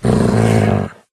mob / wolf / growl2.ogg
growl2.ogg